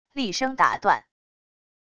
厉声打断wav音频